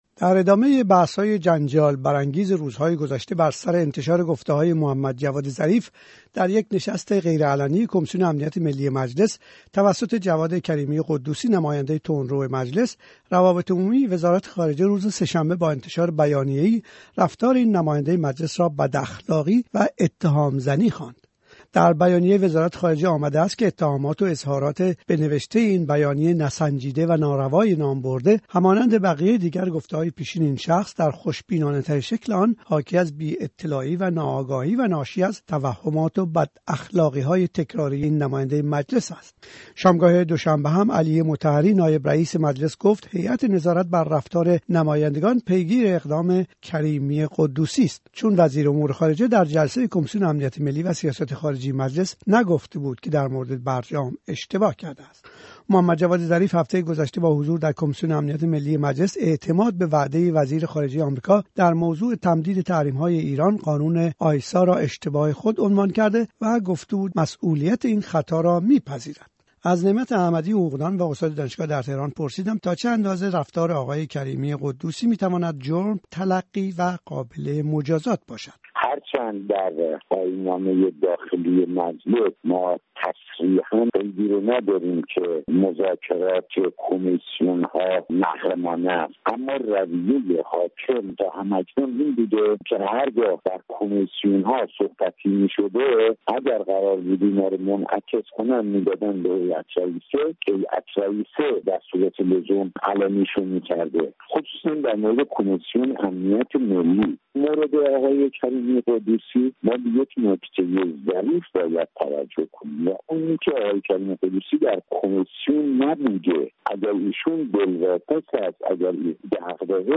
گزارشی از انتشار بیانیه کمیسیون امنیت ملی و اختلاف بر سر آن